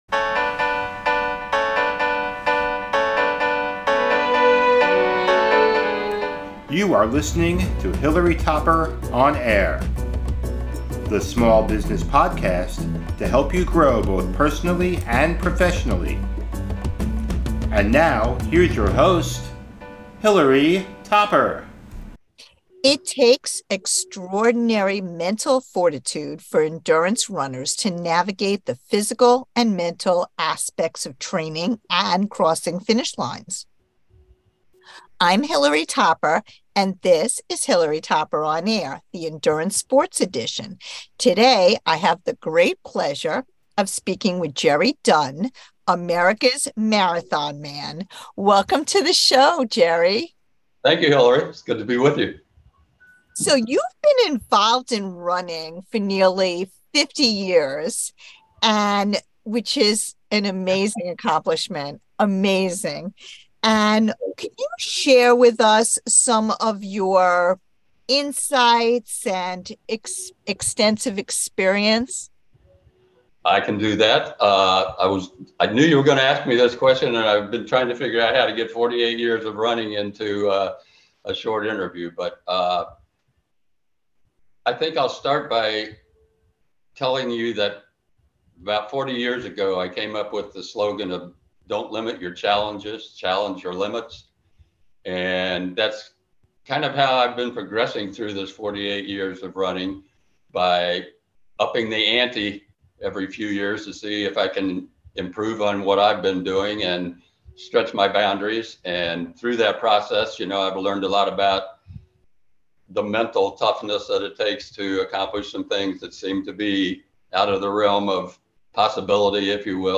In the interview, he shares the mental strategies he employs to endure long distances. His use of meditation and yoga techniques to overcome mental and physical challenges demonstrates the power of a strong mind in achieving remarkable physical feats.